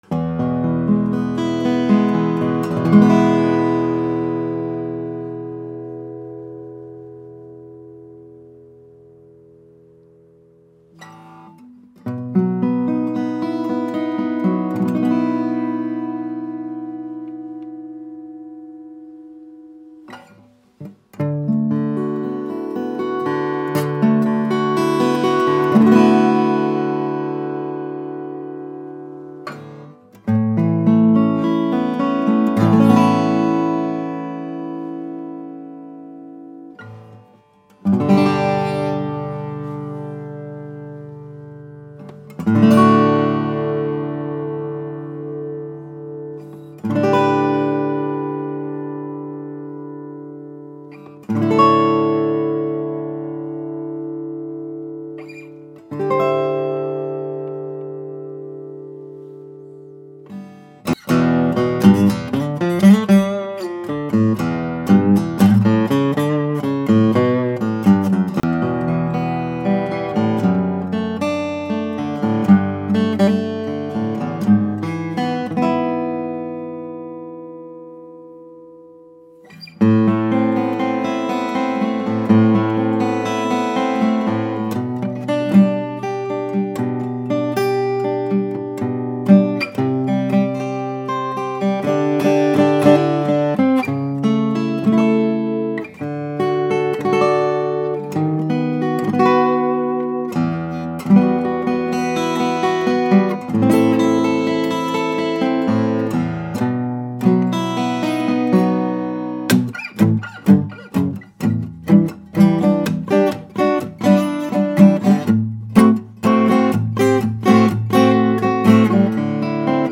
Santa Cruz 000 12-fret offers an open and airy tone while delivering a surprising amount of overall volume and bass response.
Description Classic Santa Cruz 000 12-fret built with premium Indian Rosewood and Sitka Spruce.
Her tone is open, mature and warm with decent bass notes and that typical warm high sparkle resembling piano-like clarity and sustain.